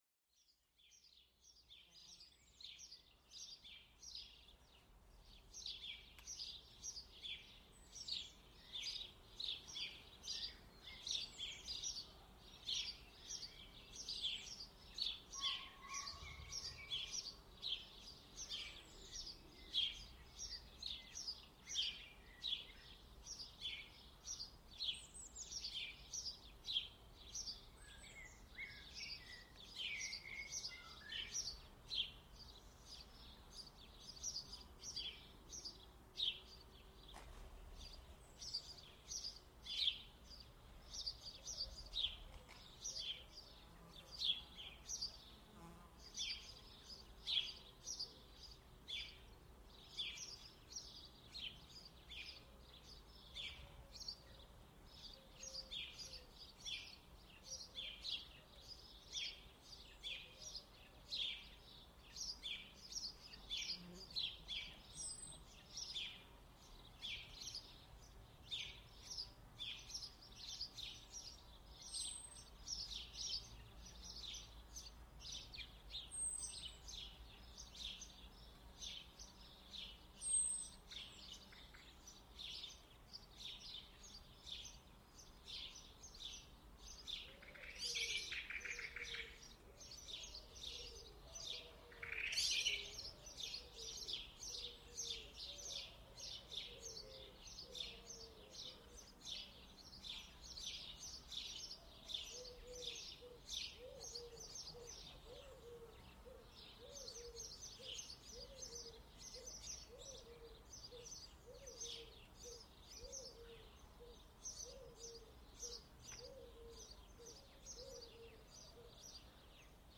Serenidad Campestre: Cantos de Aves y Bienestar
Experimenta la tranquilidad del campo a través de los melódicos cantos de aves. Este episodio te sumerge en una atmósfera pacífica, ideal para la relajación.